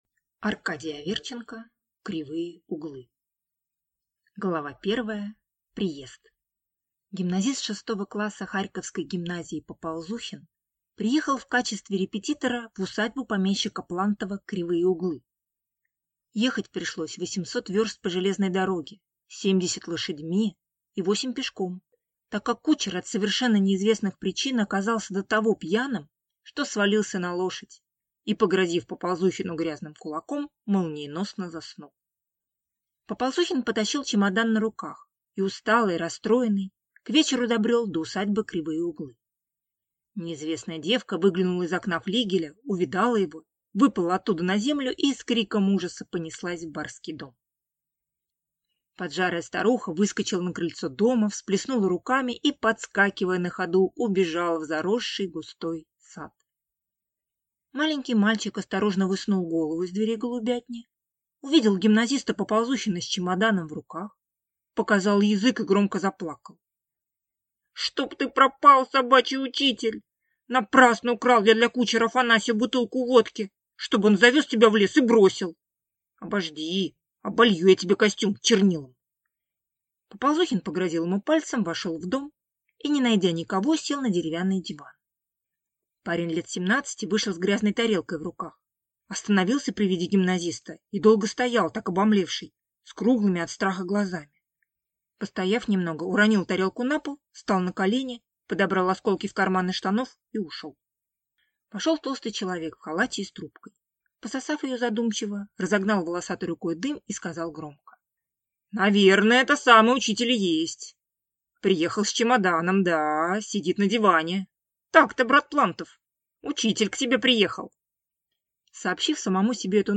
Аудиокнига Кривые Углы | Библиотека аудиокниг
Прослушать и бесплатно скачать фрагмент аудиокниги